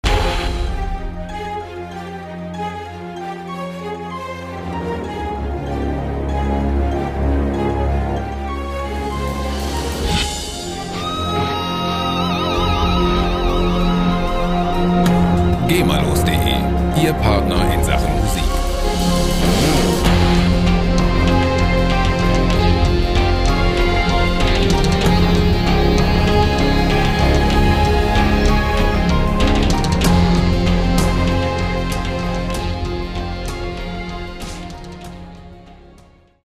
epische Musikloops lizenzfrei
Musikstil: Soundtrack
Tempo: 93 bpm